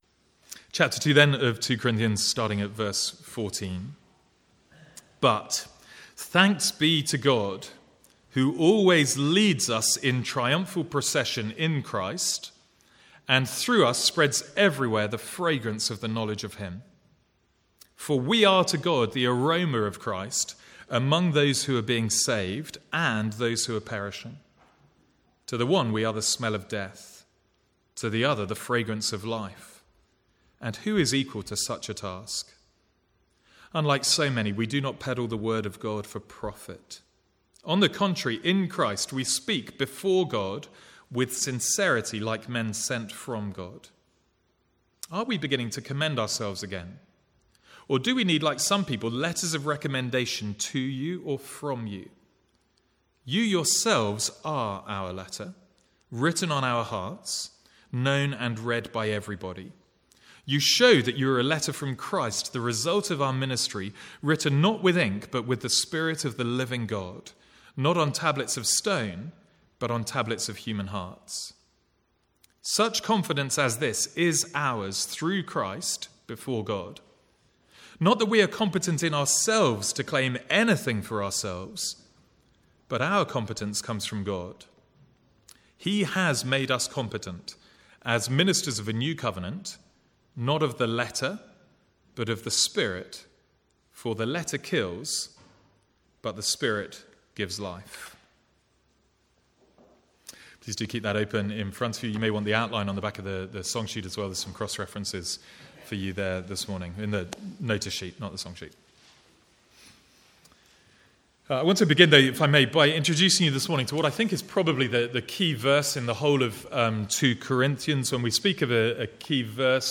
From the Sunday morning series in 2 Corinthians.
Sermon Notes